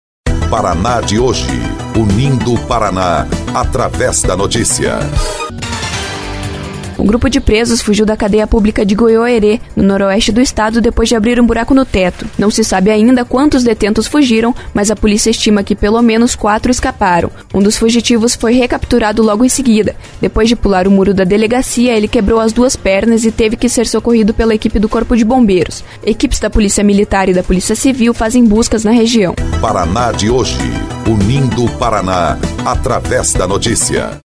03.05 – BOLETIM – Detentos fogem da cadeia pública de Goioerê